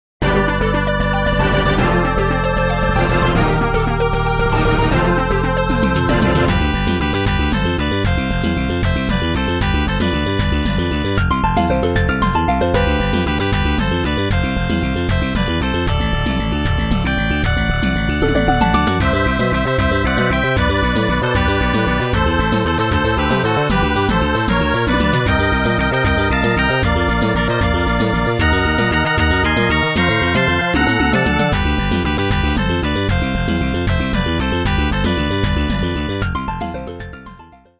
024 　Ｋ社のシューティングゲーム風（G） 06/09/13